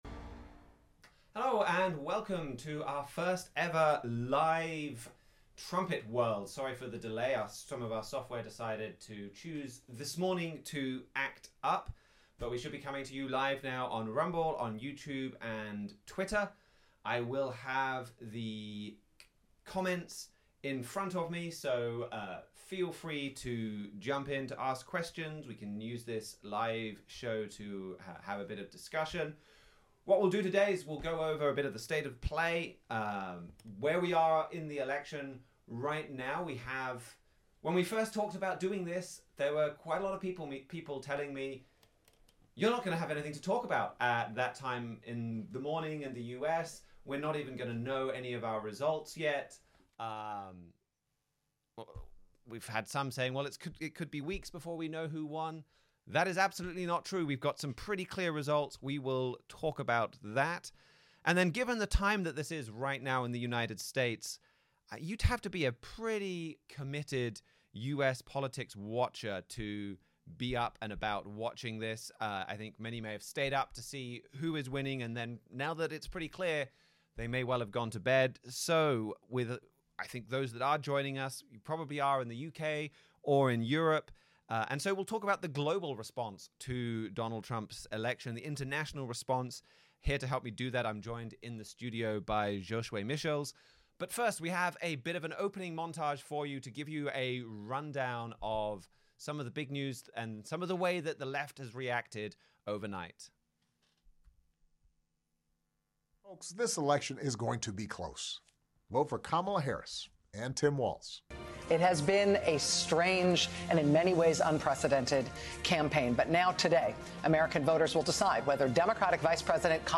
Trumpet World dives into the critical news events that leap from the pages of your Bible. In today’s special live show, we’ll update you with the latest from the U.S. election, focusing on the early reaction from European leaders.
Join the discussion as Trumpet staff members compare recent news with Bible prophecy.